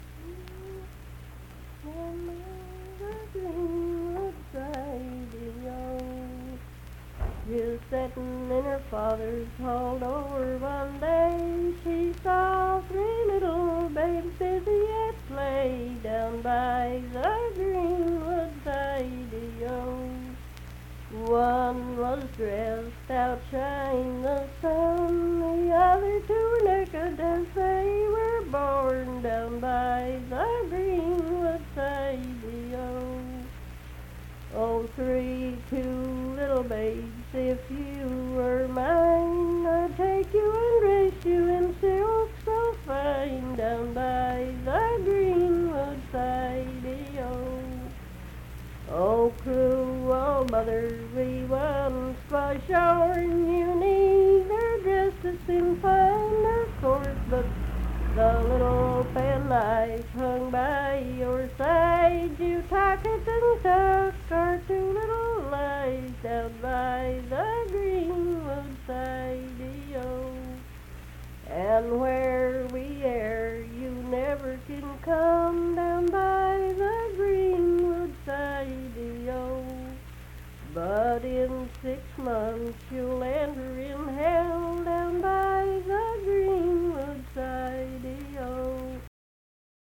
Unaccompanied vocal music performance
Verse-refrain 7(2-3w/R).
Voice (sung)